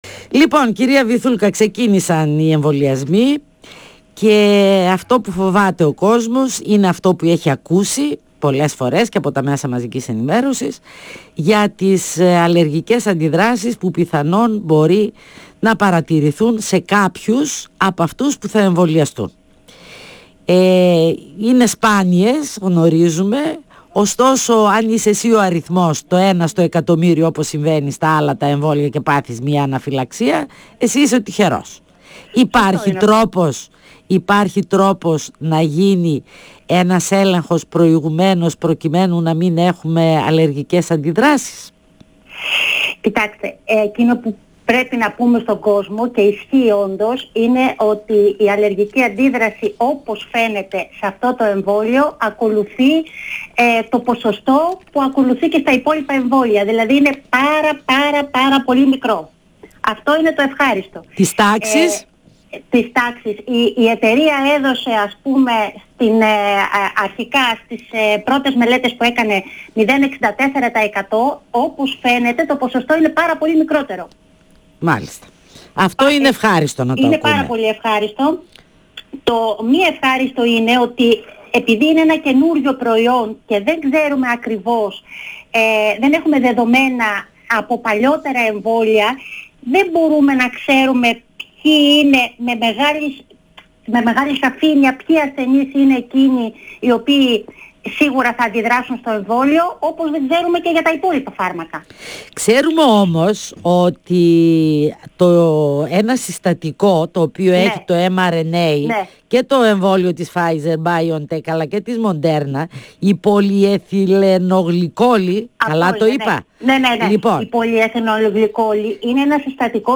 στον 102 fm της ΕΡΤ3